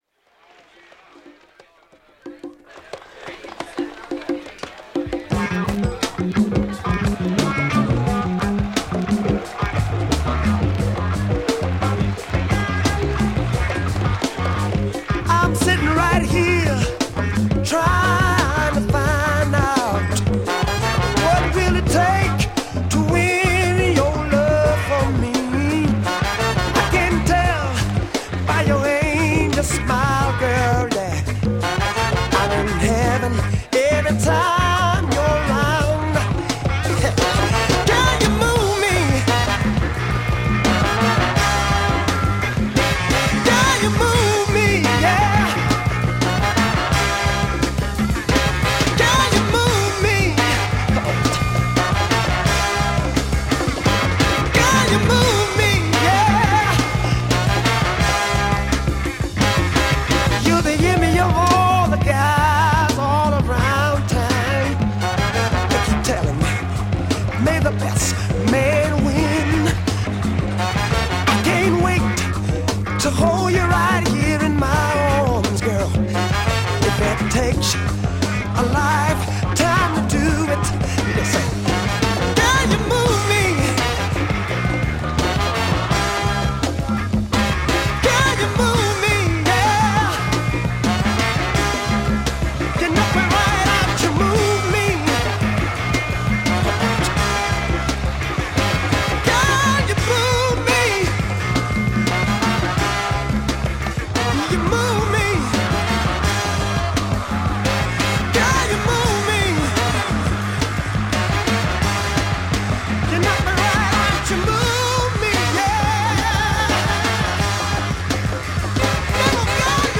French funk
Rare French Afro funk single 2Siders!!